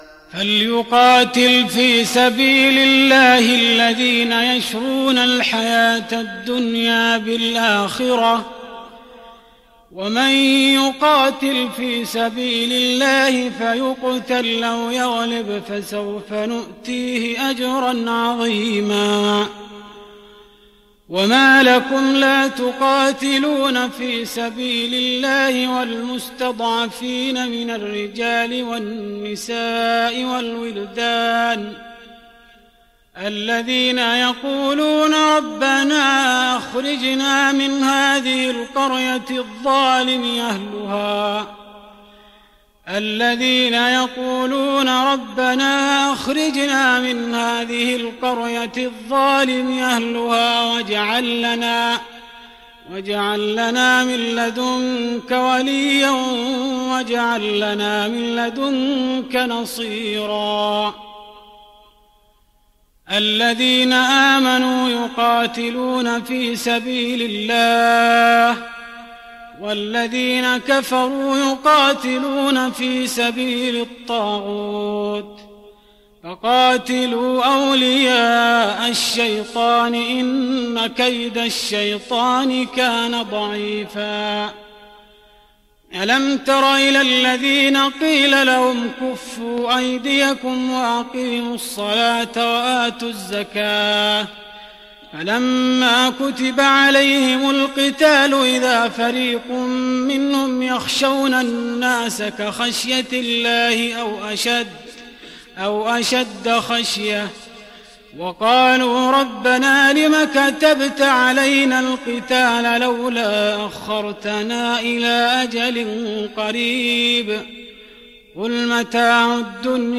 تهجد رمضان 1415هـ من سورة النساء (74-134) Tahajjud Ramadan 1415H from Surah An-Nisaa > تراويح الحرم النبوي عام 1415 🕌 > التراويح - تلاوات الحرمين